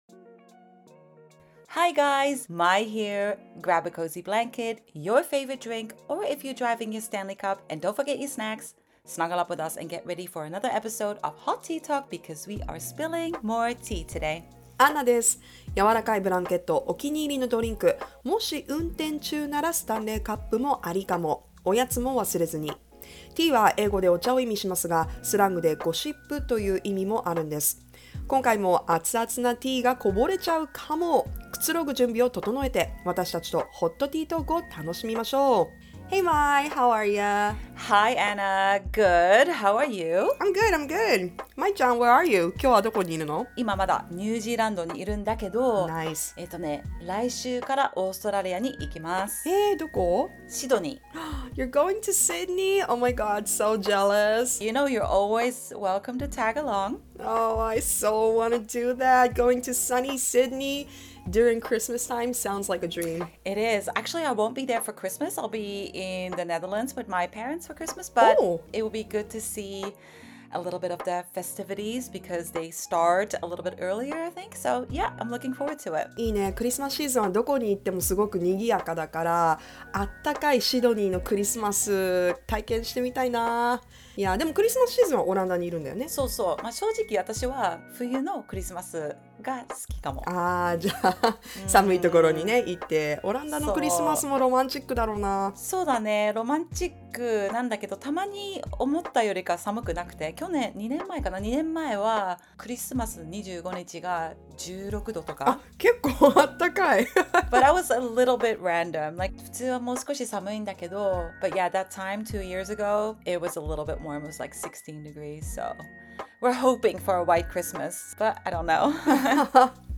English and Japanese bilingual podcast.